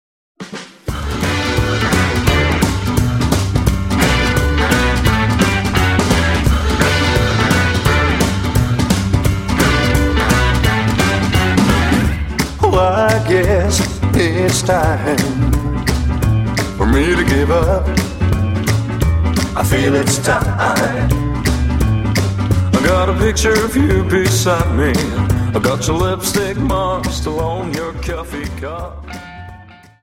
Jive 43 Song